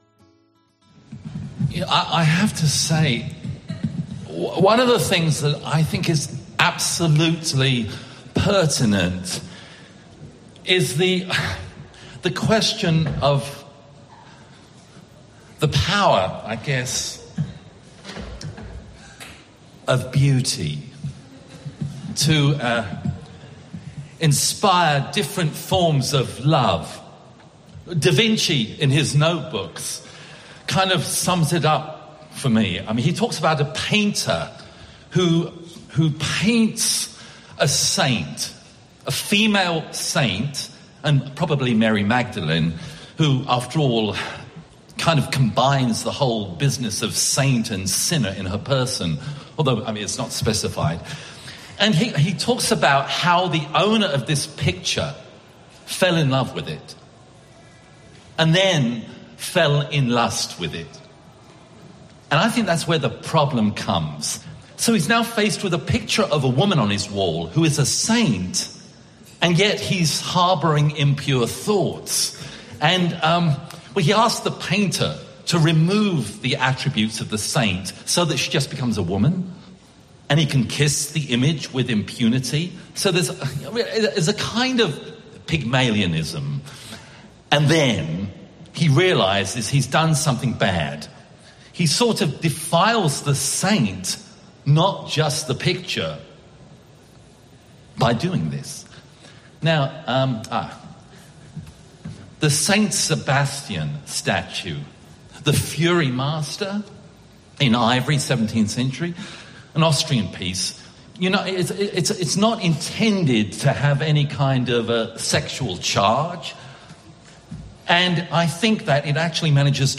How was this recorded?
—from the Civilians’s Let Me Ascertain You cabaret at the Metropolitan Museum of Art.